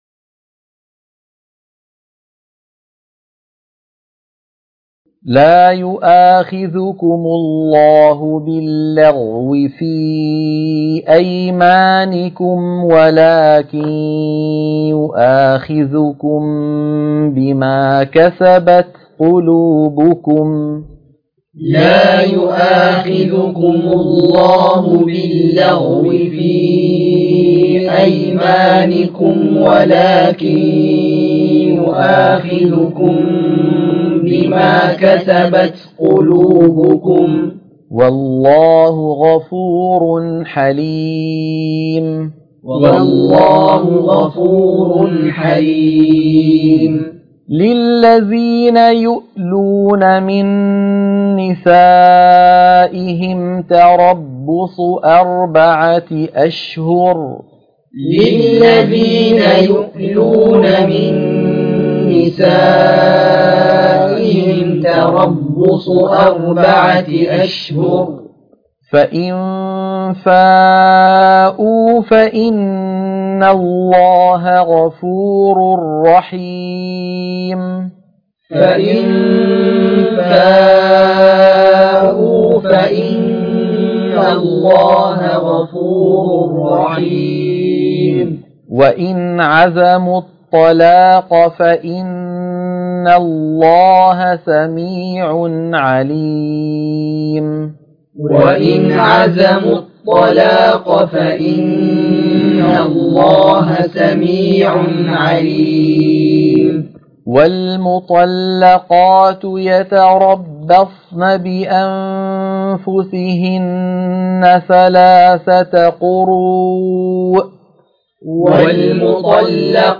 عنوان المادة تلقين سورة البقرة - الصفحة 36 _ التلاوة المنهجية